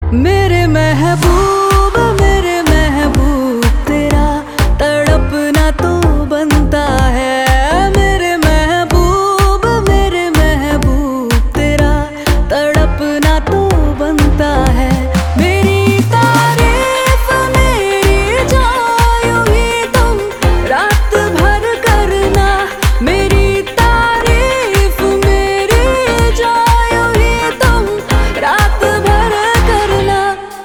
Categories: Bollywood Ringtones